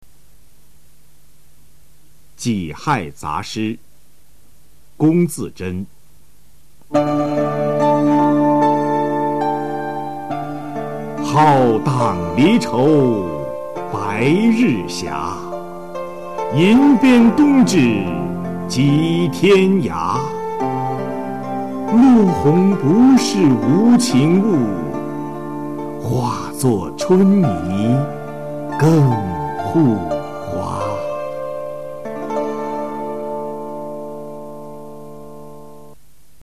《己亥杂诗·浩荡离愁白日斜》原文与译文（含朗读）